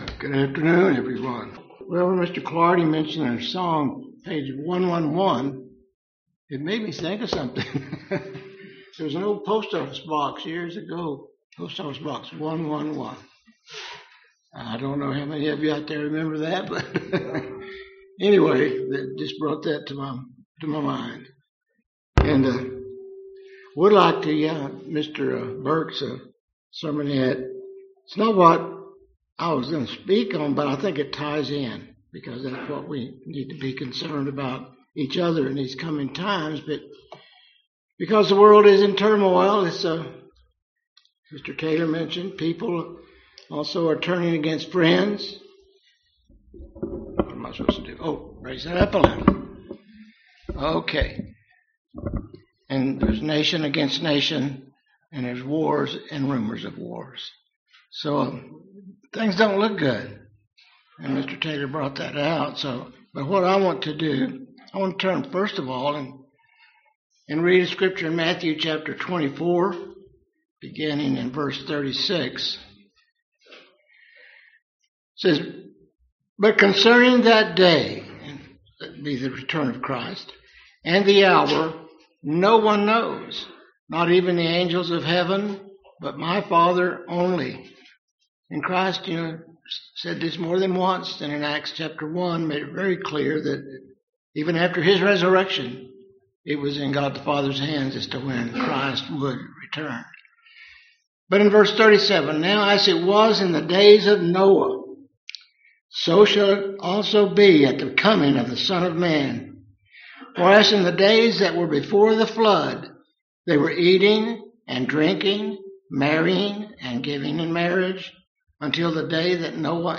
Sermons
Given in Lubbock, TX